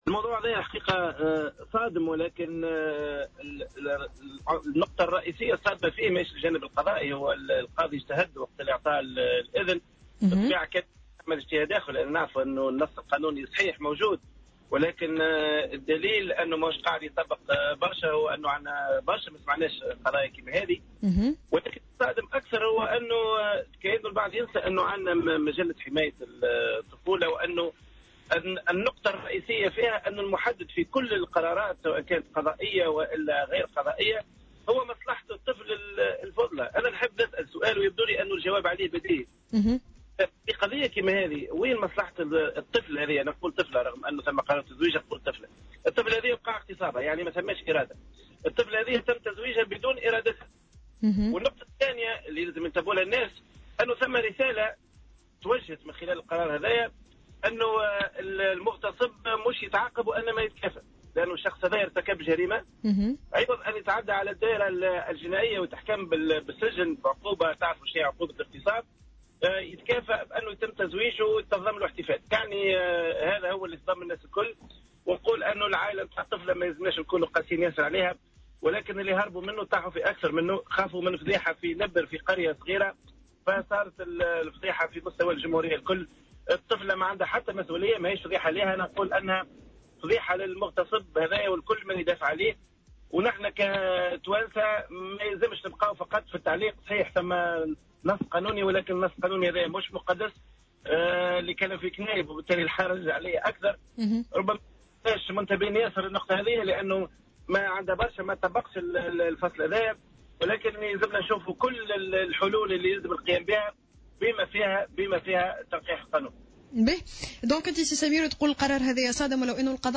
وأضاف في تصريح اليوم ل"الجوهرة أف أم" أن القاضي اجتهد وأن النص القانوني الذي يجيز ذلك موجود لكنه ليس مقدّسا ويجب تنقيحه.